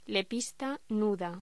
Locución: Lepista nuda